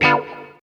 137 GTR 1 -R.wav